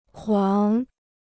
狂 (kuáng): mad; wild.